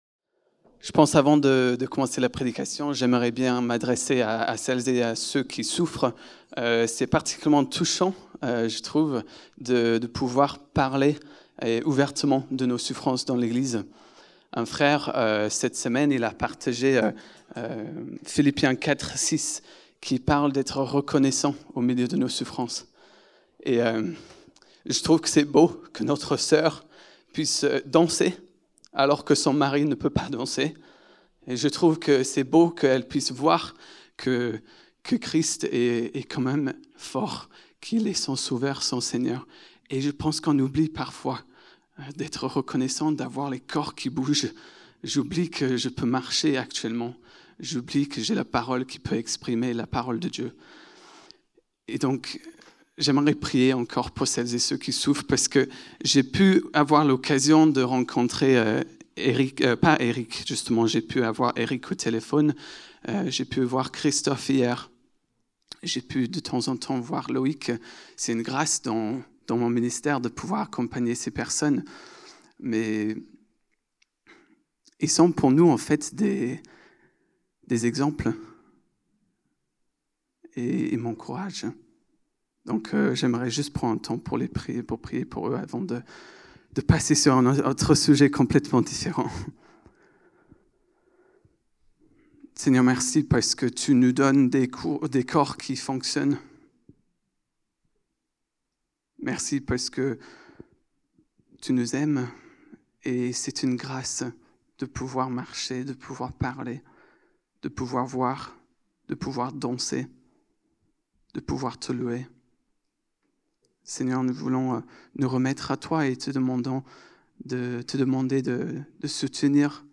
Culte du 19 octobre 2025, prédication